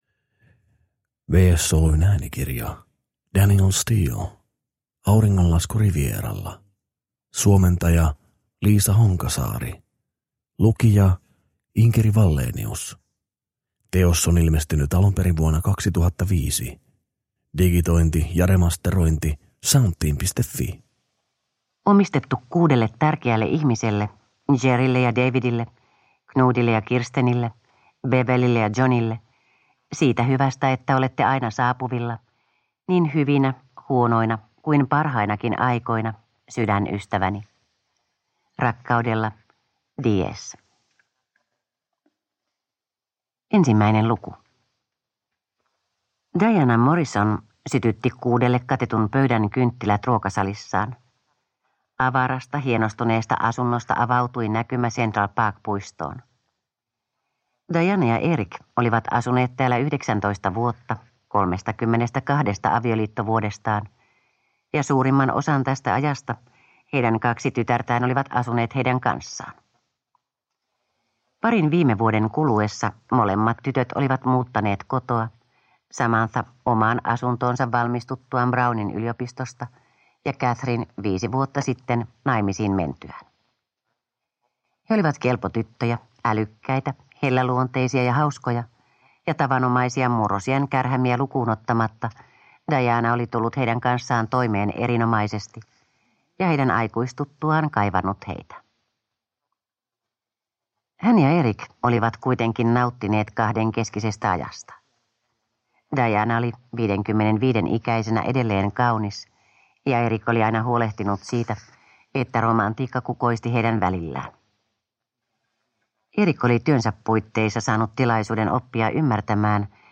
Auringonlasku Rivieralla (ljudbok) av Danielle Steel